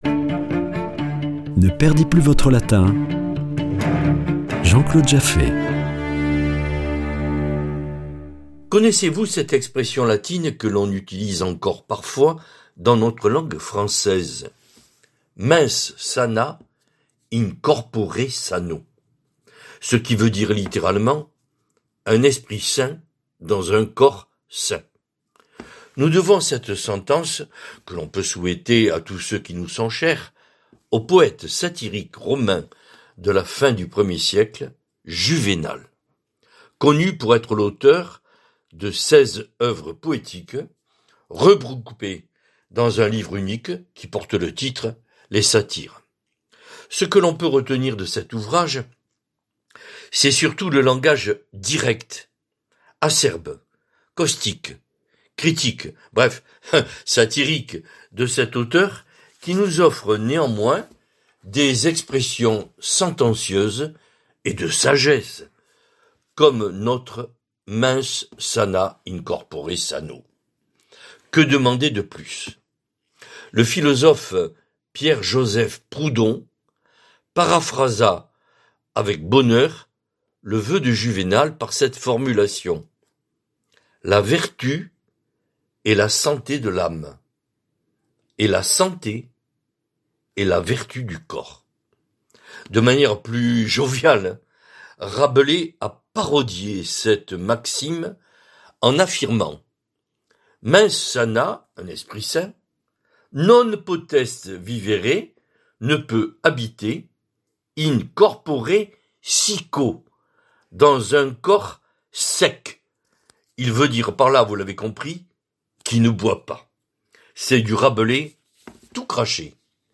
Chronique Latin
Chroniqueur